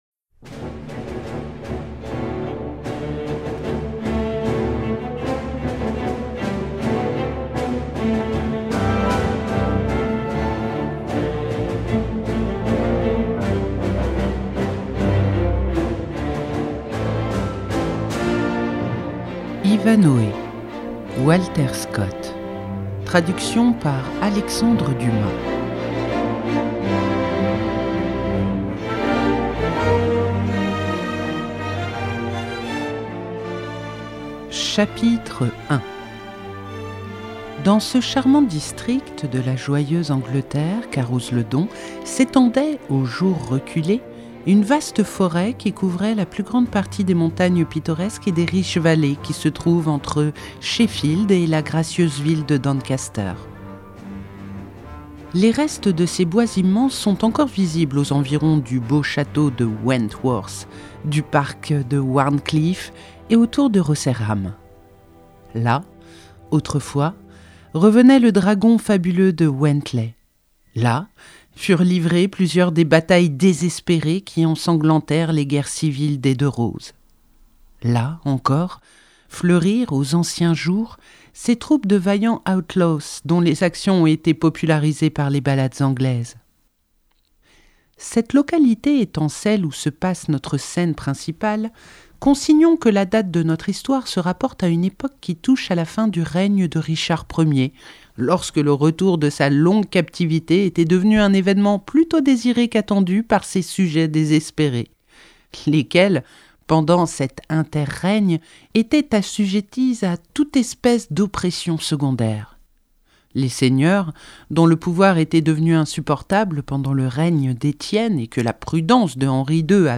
🎧 Ivanhoé – Walter Scott - Radiobook